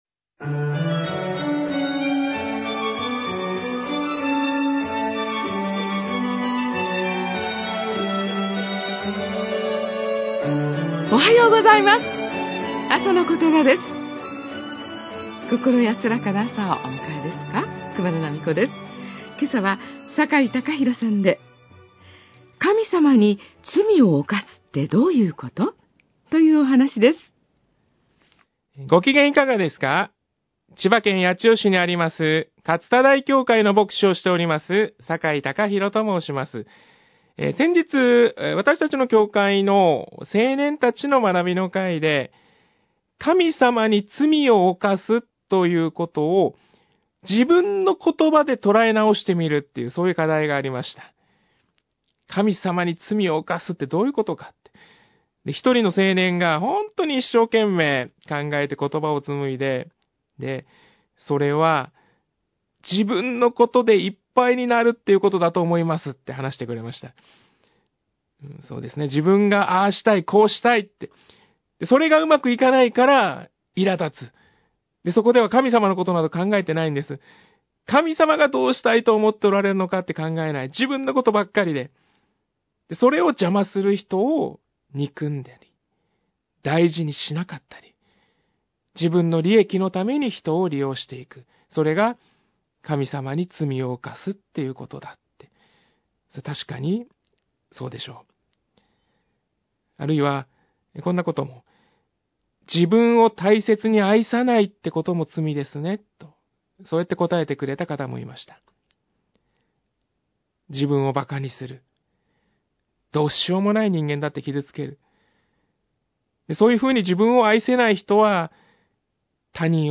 あさのことば 2016年3月3日（木）放送
メッセージ： 神様に罪を犯すってどういうこと？